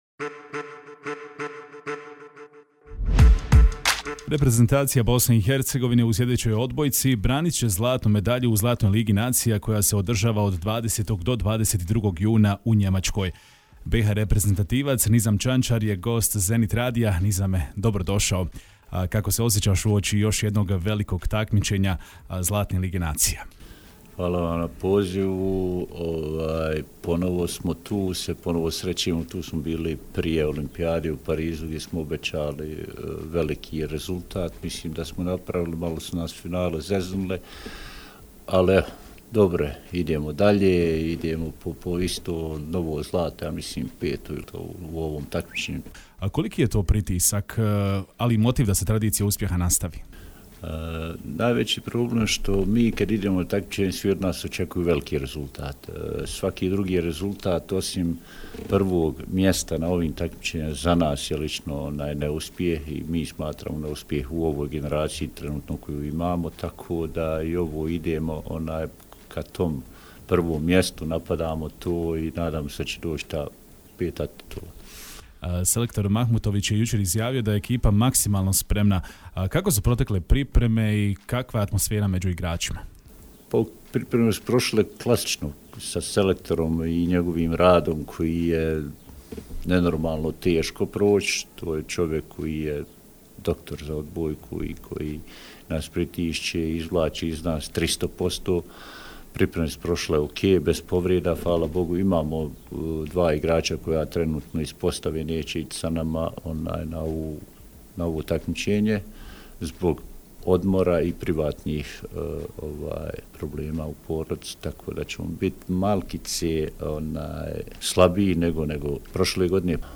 u razgovoru za Radio Zenit istakao je da su očekivanja velika, ali i da motivacije ne manjka.